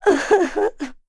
Laudia-Vox_Sad.wav